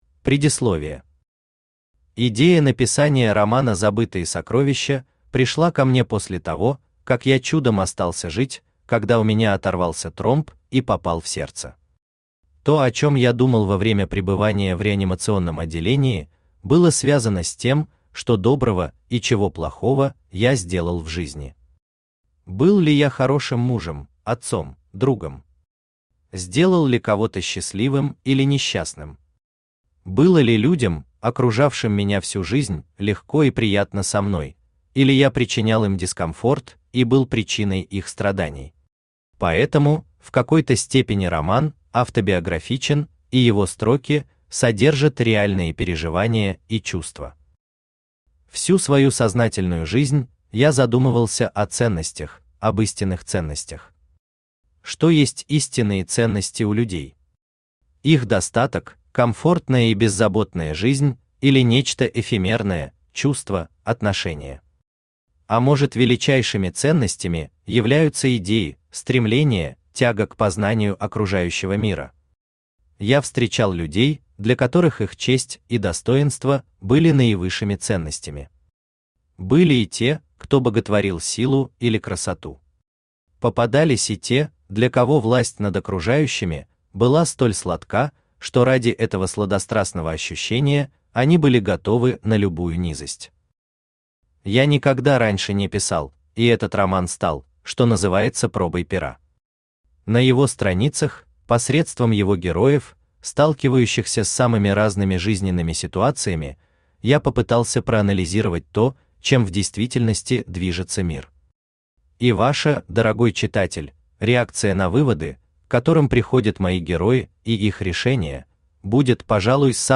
Аудиокнига Забытые сокровища | Библиотека аудиокниг
Aудиокнига Забытые сокровища Автор Андрей Юрьевич Чубарь Читает аудиокнигу Авточтец ЛитРес.